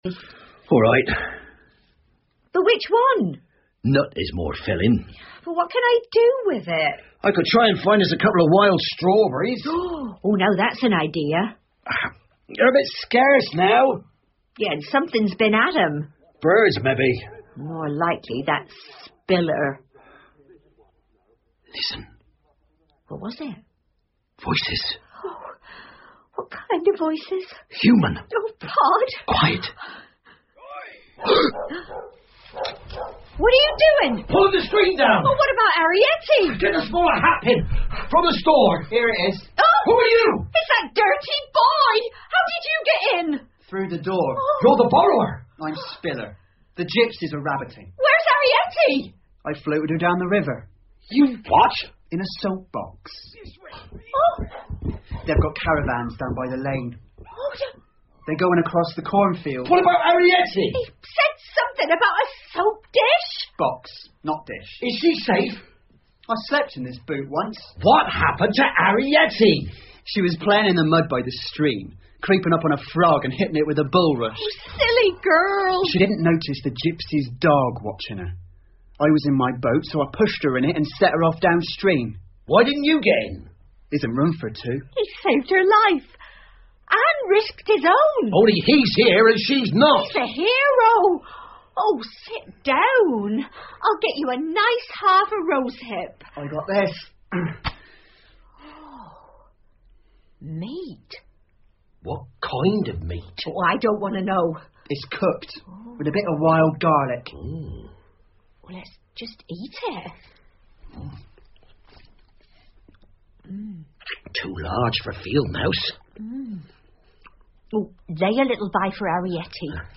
借东西的小人 The Borrowers 儿童广播剧 15 听力文件下载—在线英语听力室